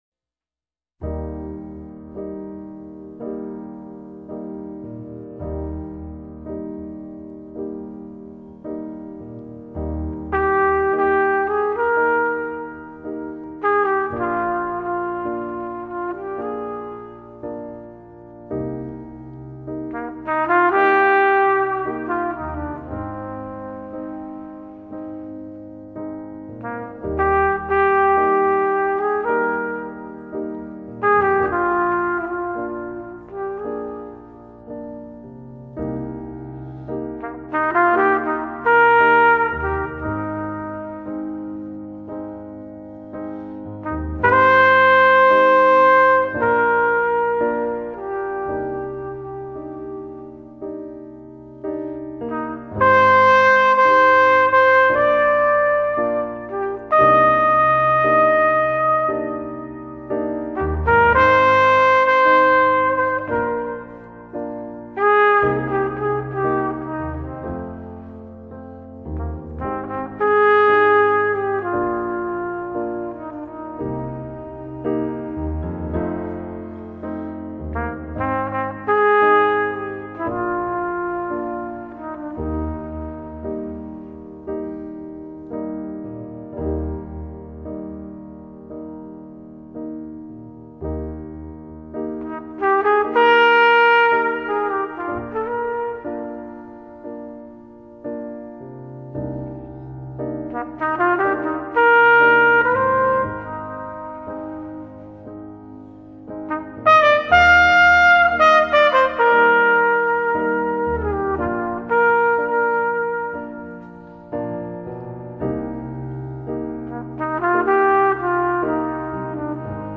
音乐风格：Jazz